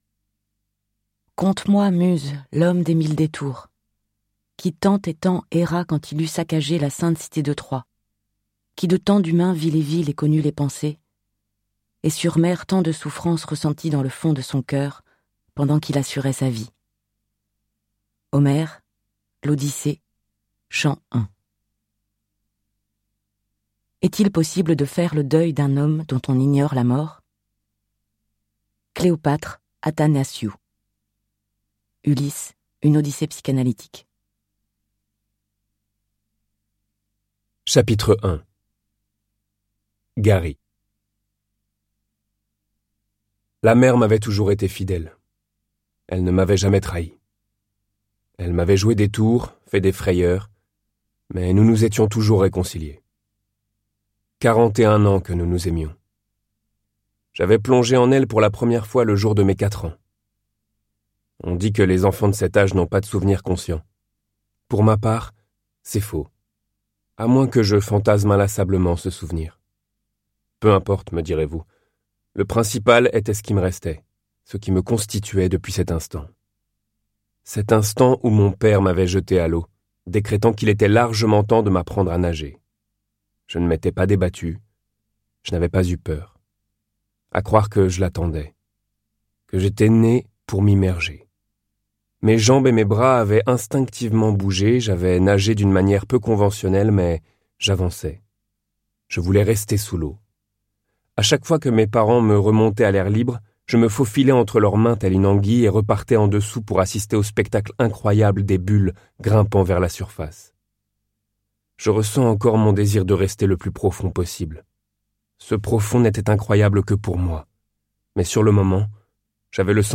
Click for an excerpt - L'Homme des Mille Détours de Agnès MARTIN-LUGAND